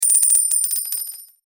获得金币.mp3